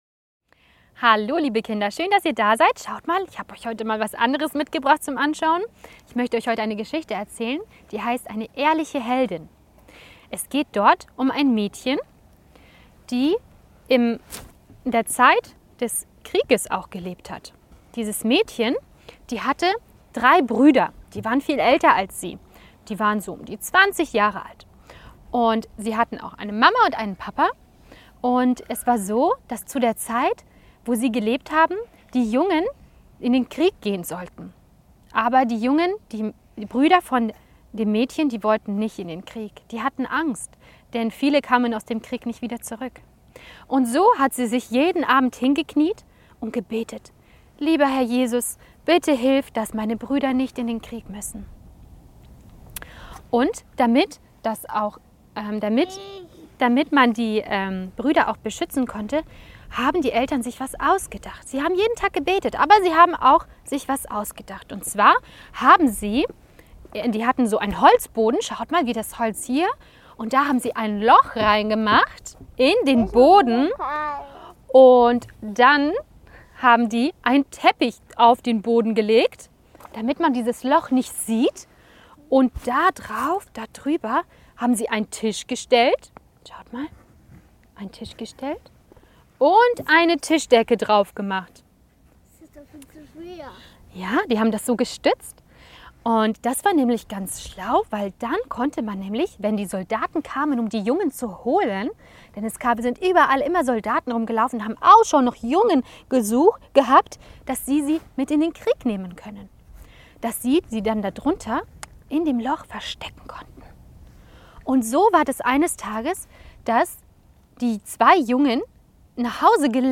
Wahre Kurzgeschichten für Kinder